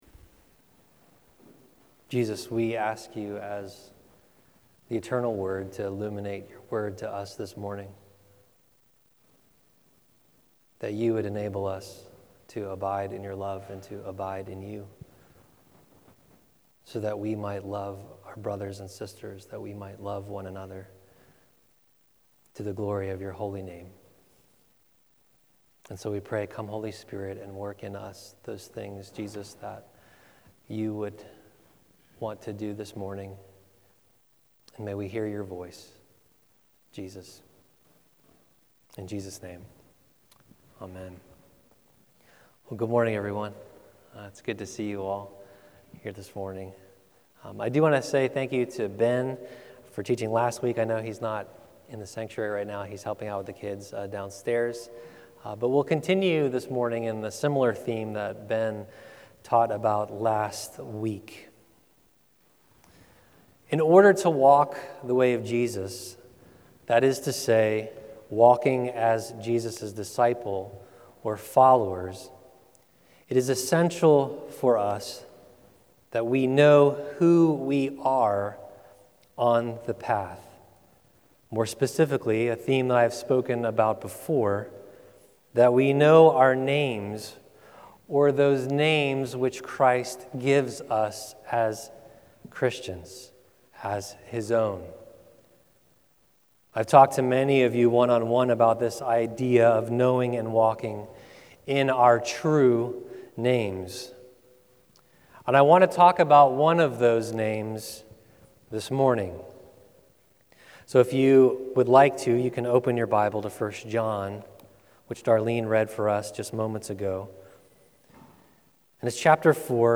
Download Download Easter 2024 Current Sermon 6th Sunday of Easter